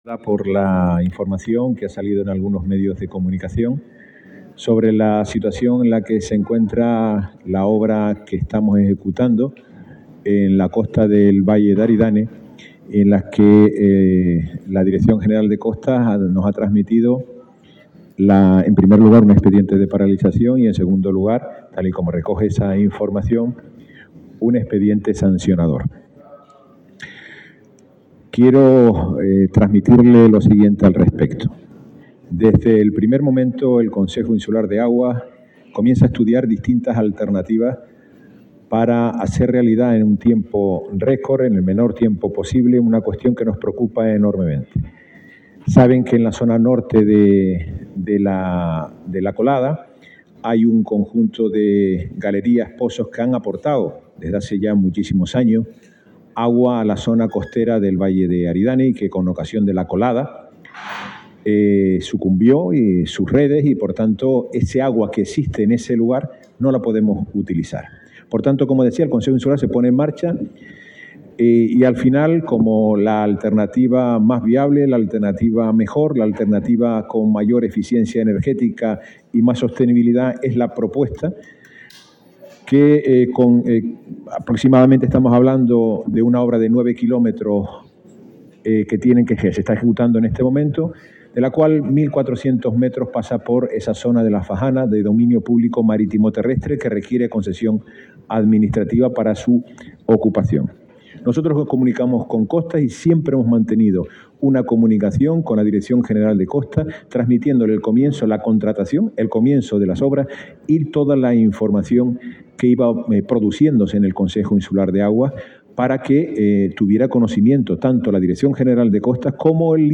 El consejero de Aguas del Cabildo de La Palma, Carlos Cabrera, ha comparecido en la mañana de hoy tras las informaciones que han aparecido en algunos medios de comunicación,